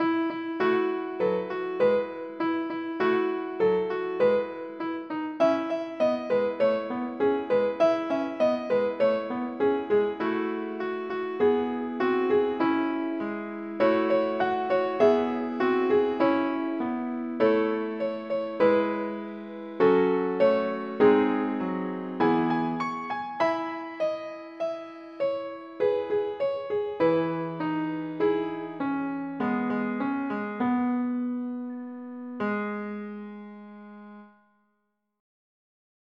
фонограмму нотной записи (минус) русской народной песни